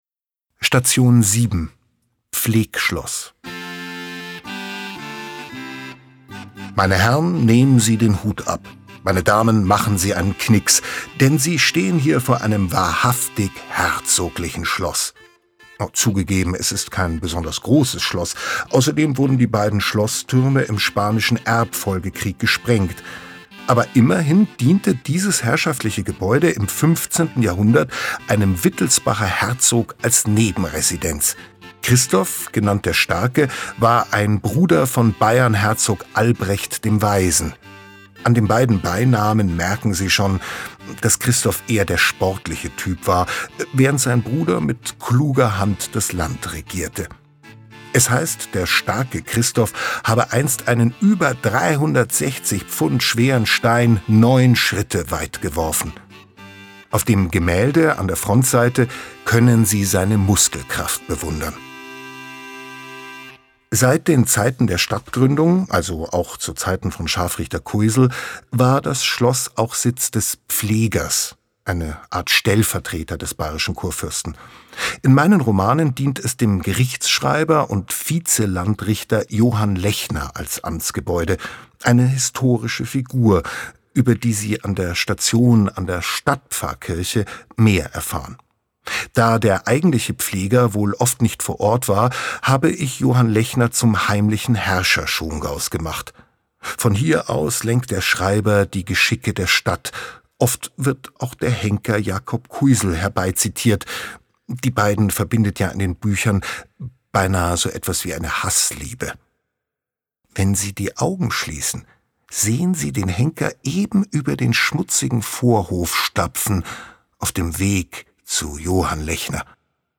Audiokommentar Oliver Pötzsch Pflegschloss
Audiokommentar zur Station 7, Pflegschloss
Audioguide_Schongau-07-Pflegschloss.mp3